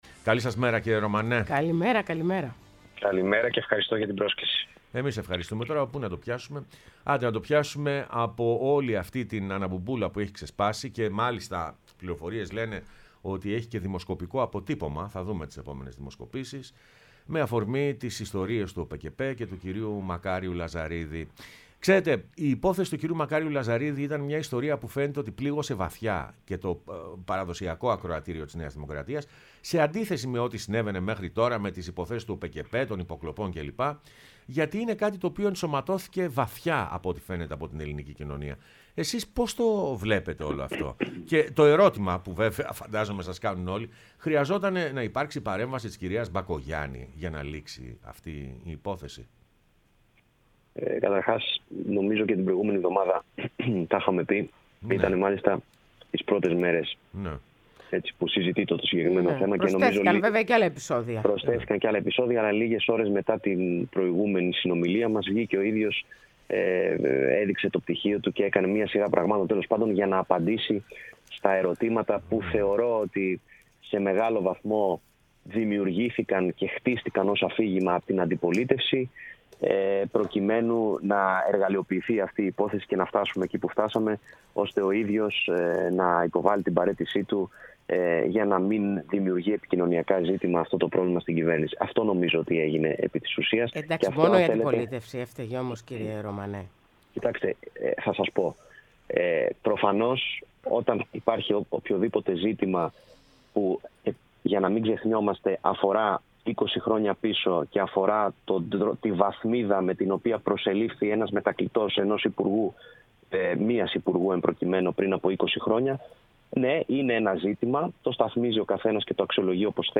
Ο Νίκος Ρωμανός, Διευθυντής Ψηφιακής Επικοινωνίας Πρωθυπουργού, μίλησε στην εκπομπή «Πρωινές Διαδρομές»